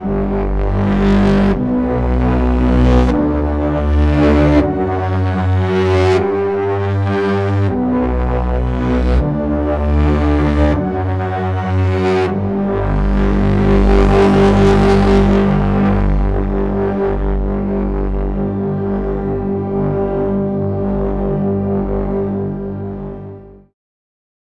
Track 07 - Synth 02.wav